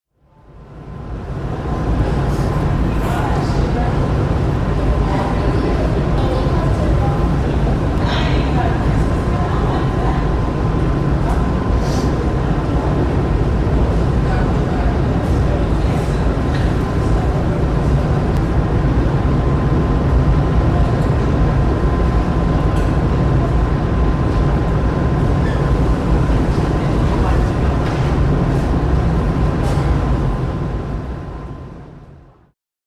Ferry to Statue of Liberty
Tags: New York New York city New York city sounds NYC Travel